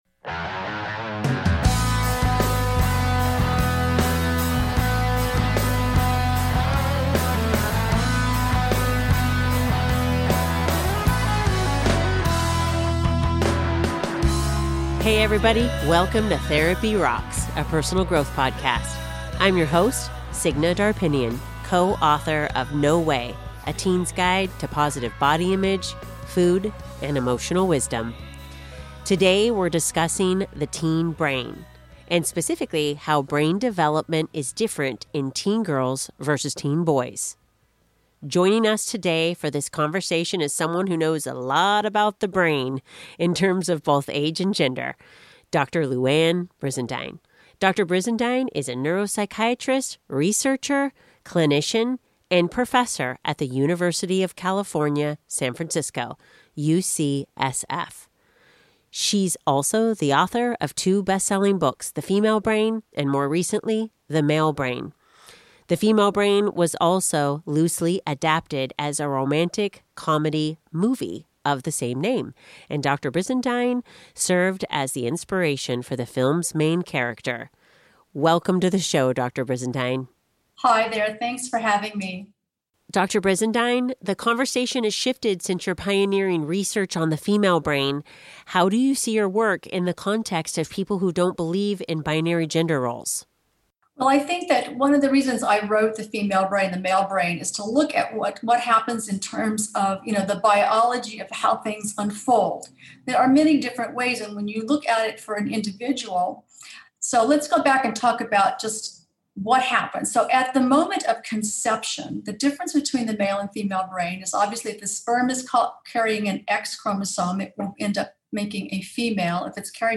The teen years can be turbulent times for parents, tune in to my conversation with Dr. Louann Brizendine for a look "under the hood" at the teen brain.